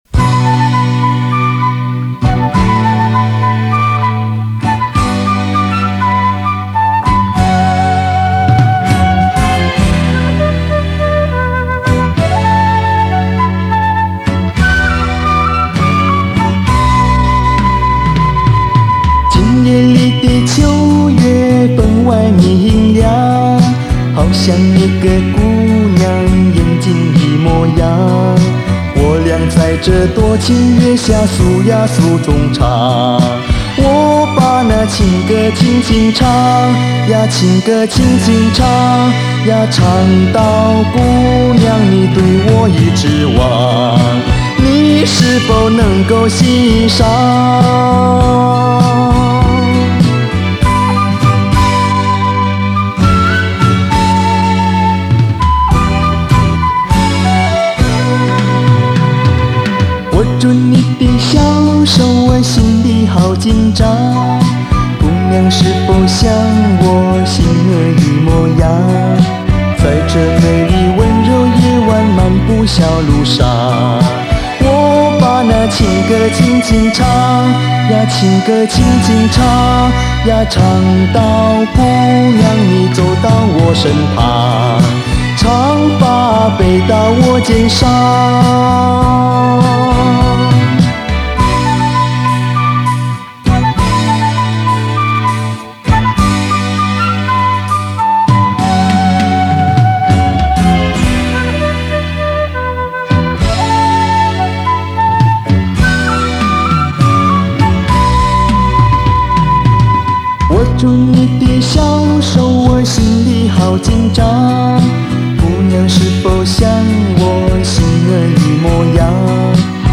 ★★★怀旧经典珍藏★★★
东南亚酒廊情歌